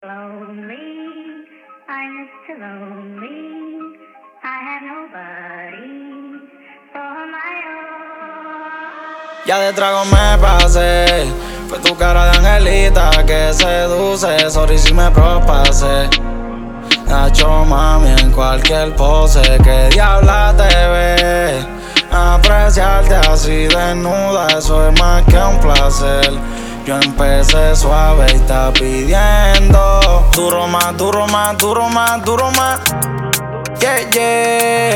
Жанр: Латино